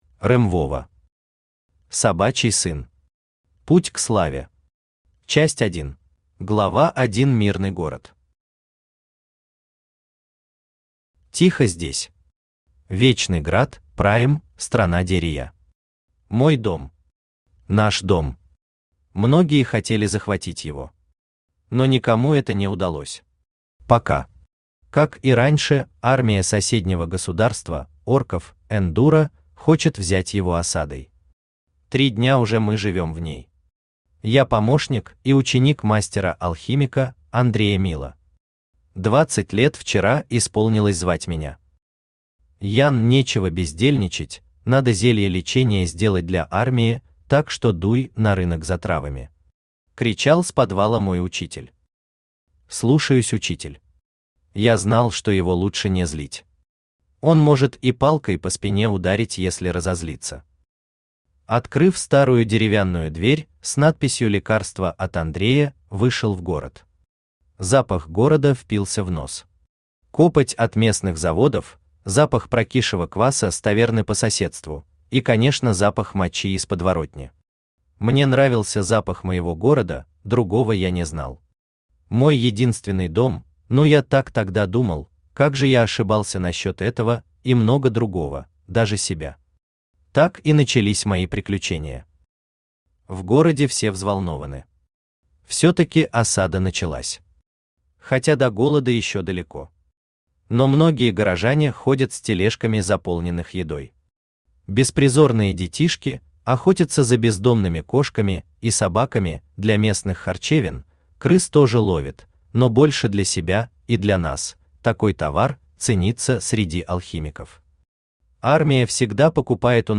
Аудиокнига Собачий сын. Путь к славе. Часть 1 | Библиотека аудиокниг
Читает аудиокнигу Авточтец ЛитРес.